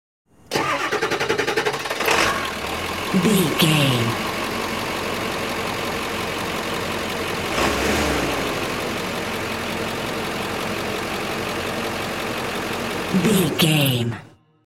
Ambulance Ext Diesel Engine Turn on off
Sound Effects
chaotic
emergency